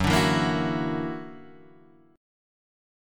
F#+7 chord